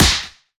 slap.wav